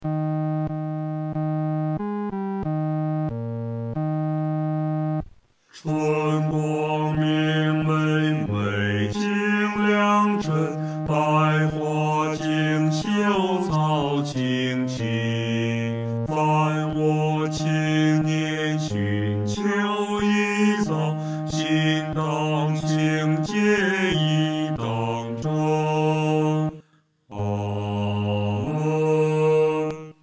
男低
本首圣诗由网上圣诗班录制